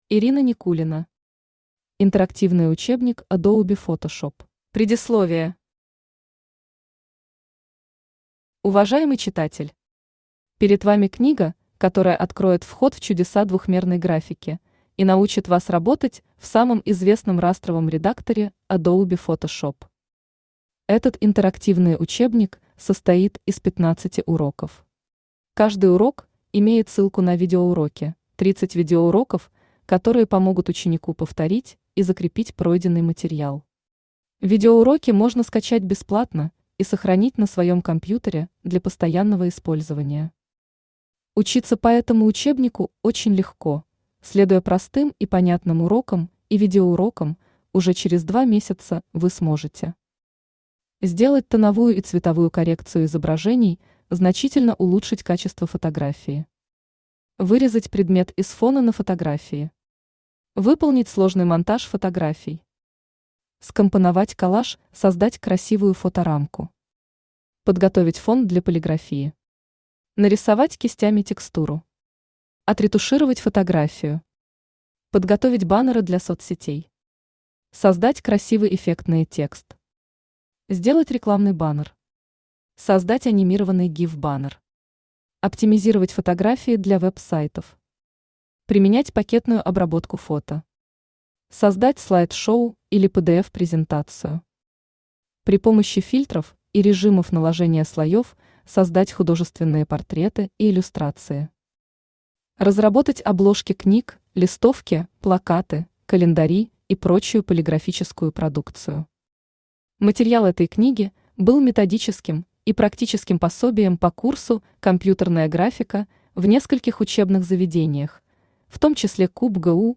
Аудиокнига Интерактивный учебник Adobe Photoshop | Библиотека аудиокниг
Aудиокнига Интерактивный учебник Adobe Photoshop Автор Ирина Никулина Читает аудиокнигу Авточтец ЛитРес.